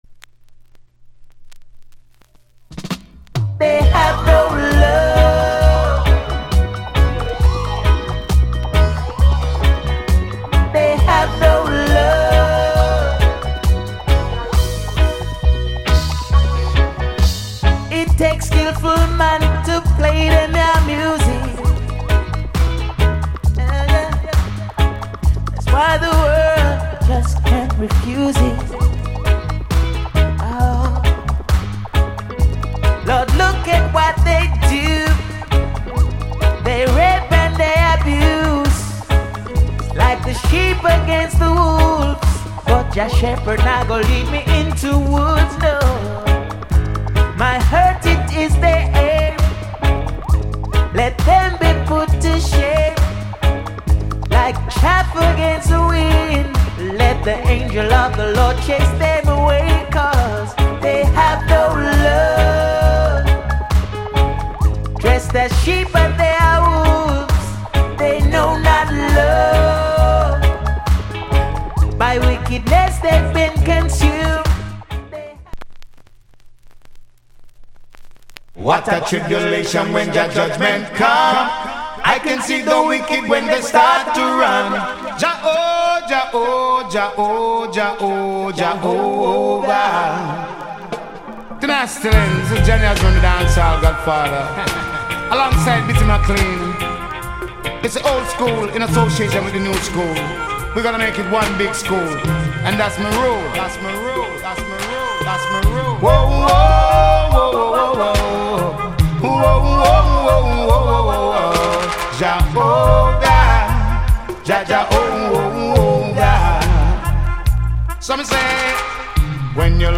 Genre Roots Rock / [A] Male Vocal [B] Combi